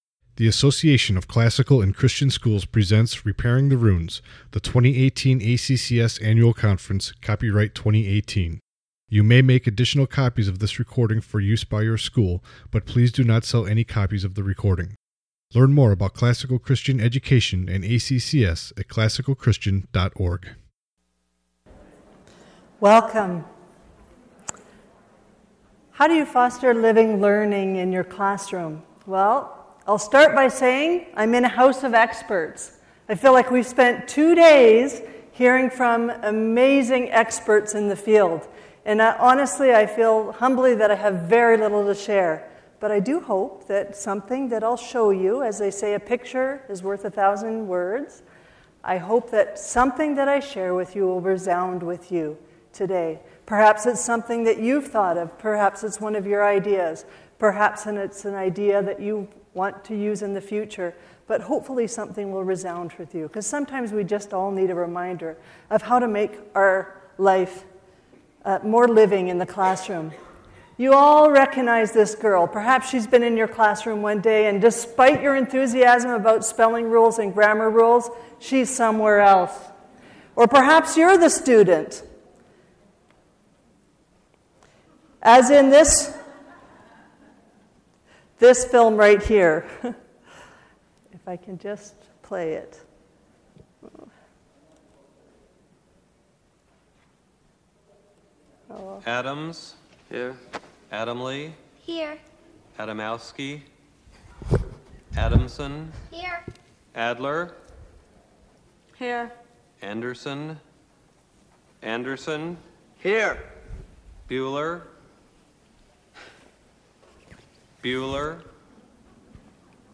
2018 Workshop Talk | 56:29 | K-6, General Classroom